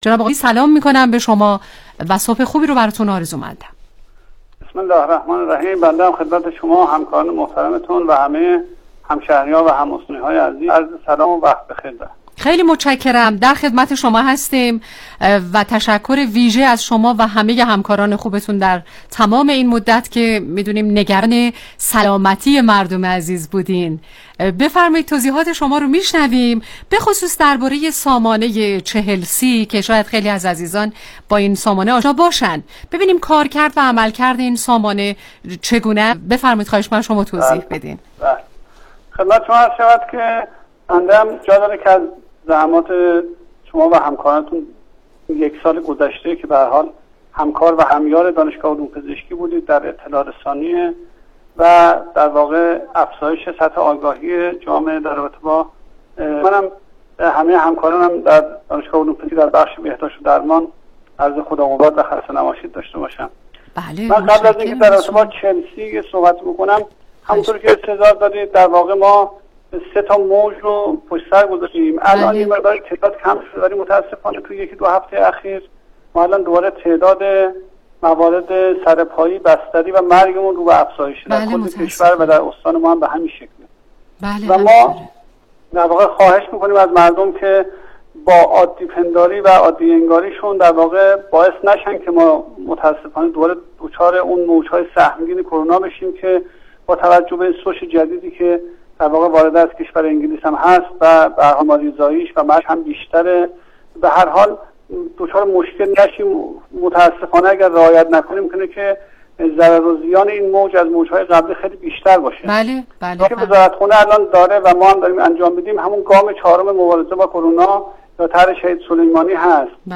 گفتگوی تلفنی برنامه کانون مهر